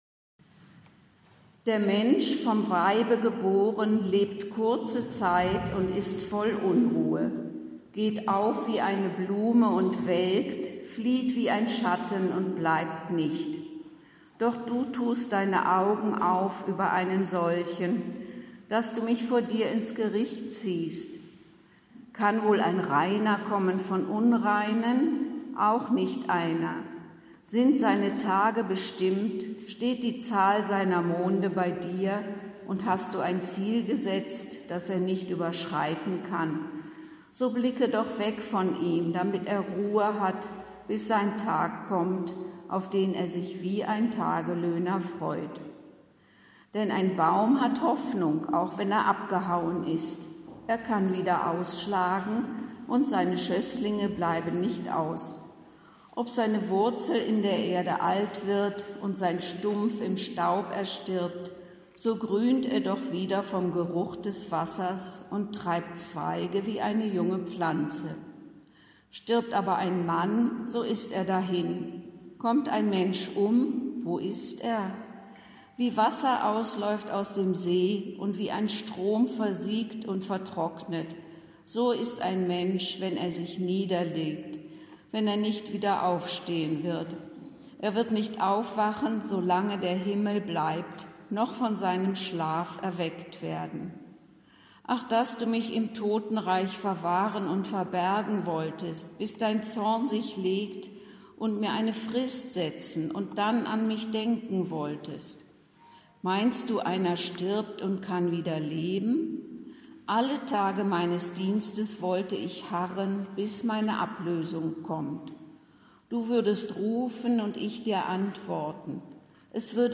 Predigt zum vorletzten Sonntag des Kirchenjahres